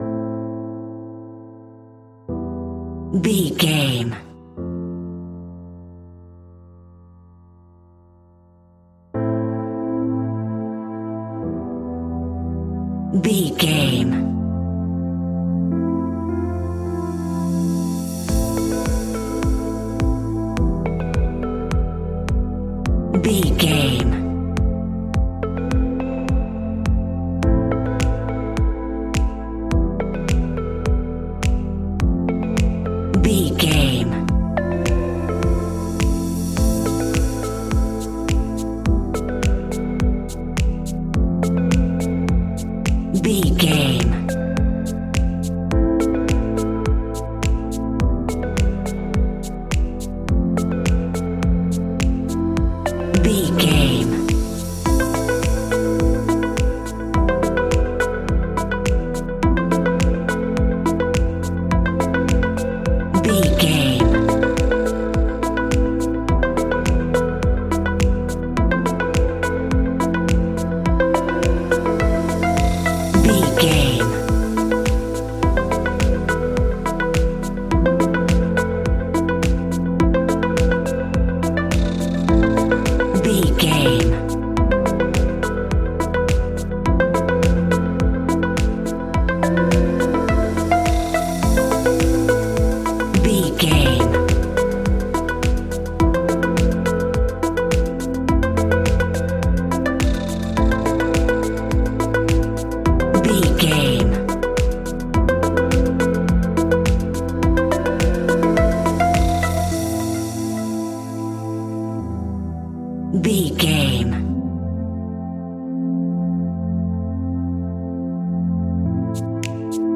Evening Tropical House Alt.
Aeolian/Minor
groovy
calm
smooth
dreamy
uplifting
piano
drum machine
synthesiser
house
electro house
synth leads
synth bass